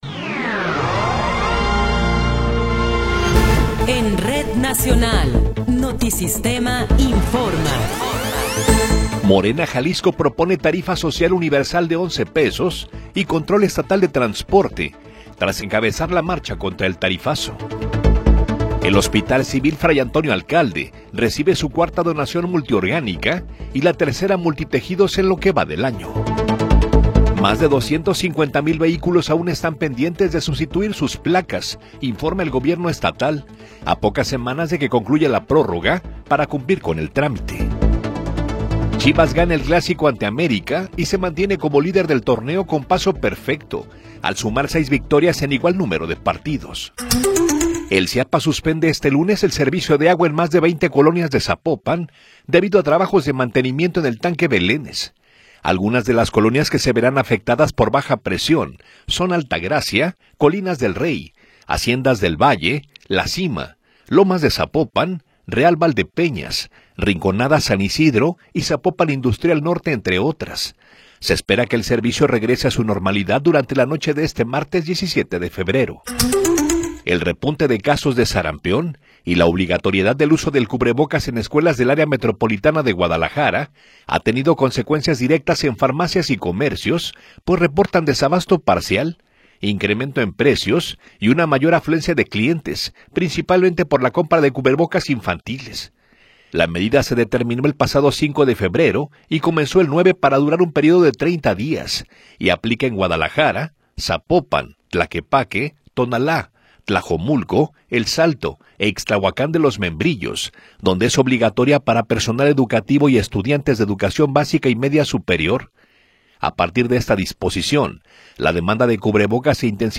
Noticiero 9 hrs. – 16 de Febrero de 2026
Resumen informativo Notisistema, la mejor y más completa información cada hora en la hora.